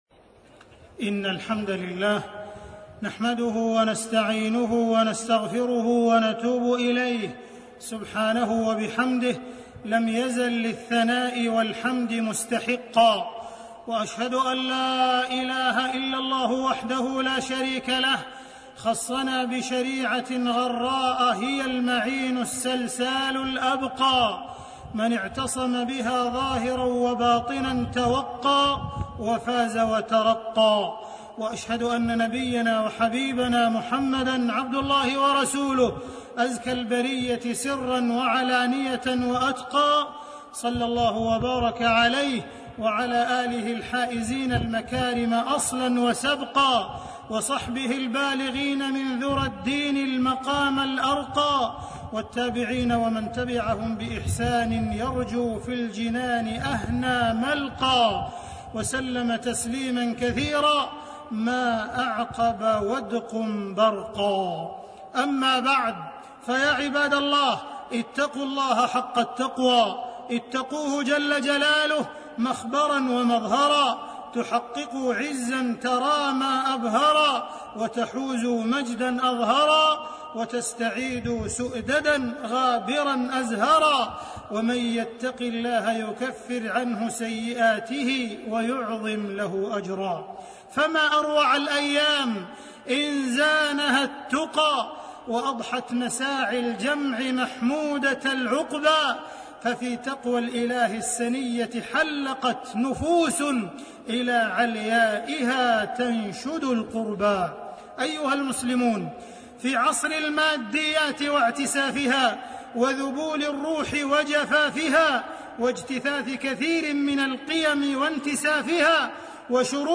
تاريخ النشر ٩ ذو القعدة ١٤٣٢ هـ المكان: المسجد الحرام الشيخ: معالي الشيخ أ.د. عبدالرحمن بن عبدالعزيز السديس معالي الشيخ أ.د. عبدالرحمن بن عبدالعزيز السديس اقتضاء القول العمل